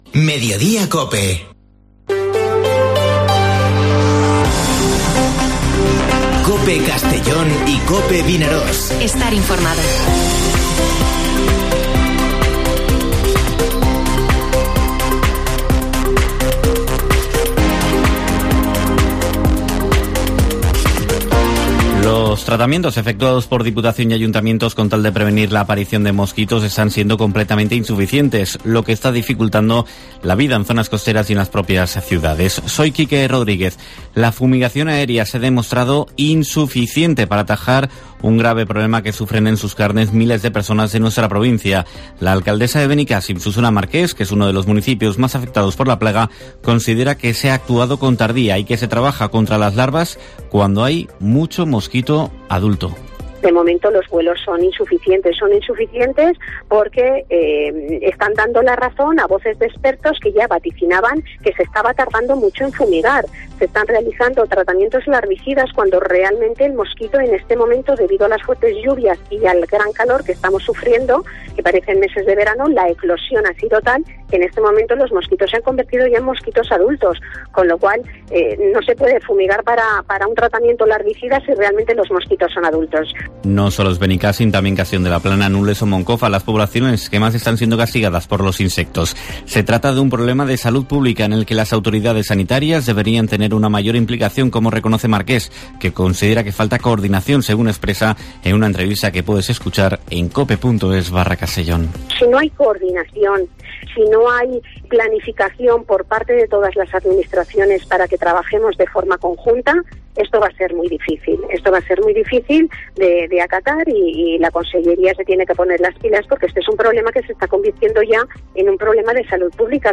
Informativo Mediodía COPE en la provincia de Castellón (19/05/2022)